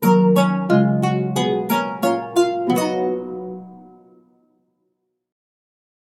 ジングル[和系音色](6) 和・民族系音色